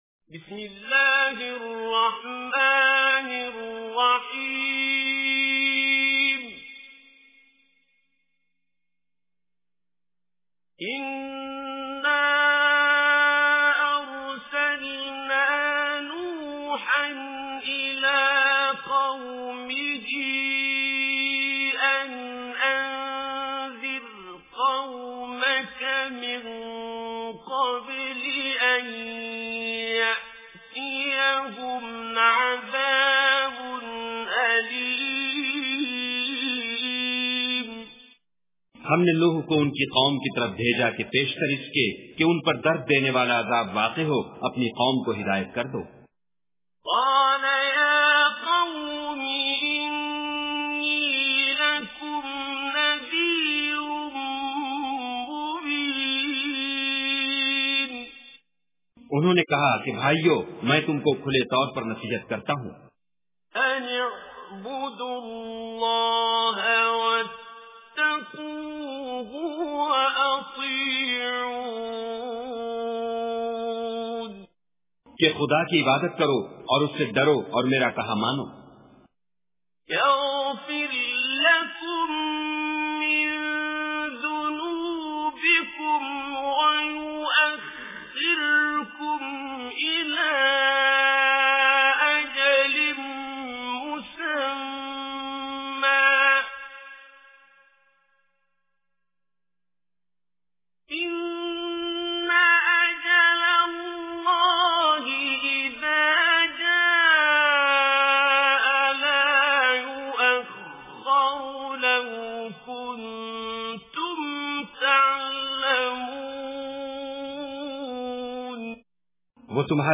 Surah Nuh Recitation with Urdu Translation